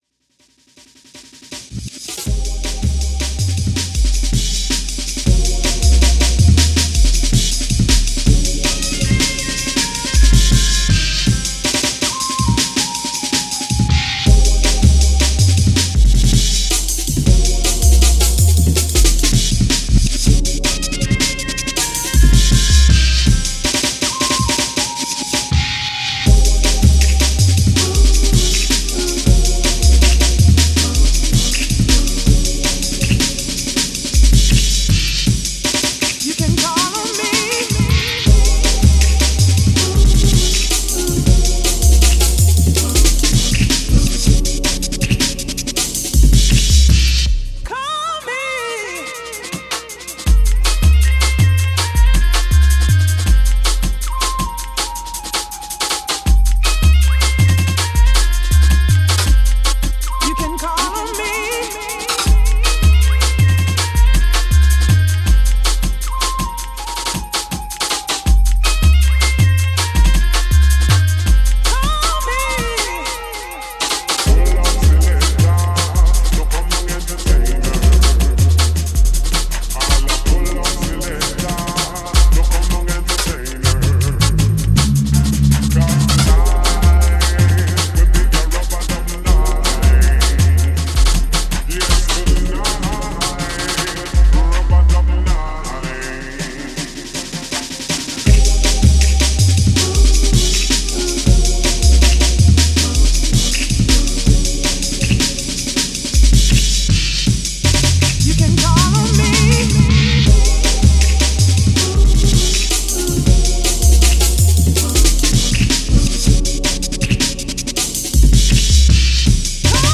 classic rave sounds